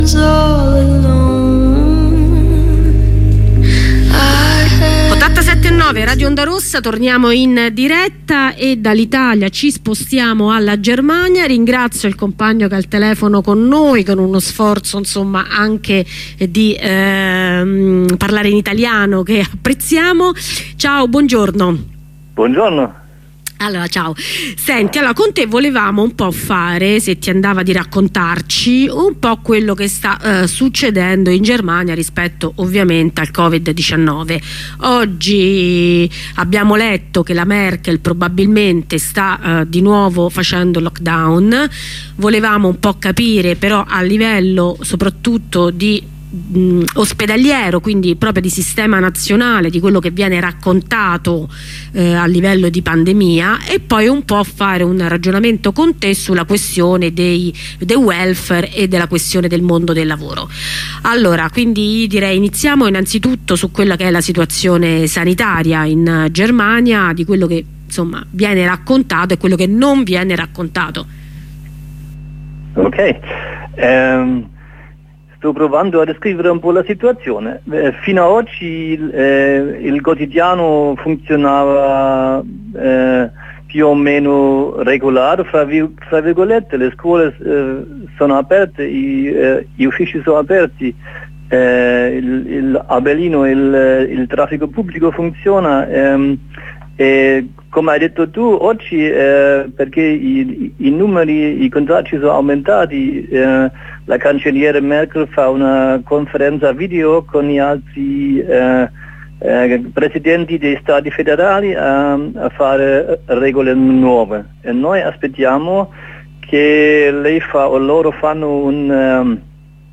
Con un compagno tedesco facciamo un punto sulla situazione della pandemia in Germania